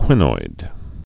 (kwĭnoid)